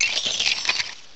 sovereignx/sound/direct_sound_samples/cries/uncomp_klefki.aif at master
uncomp_klefki.aif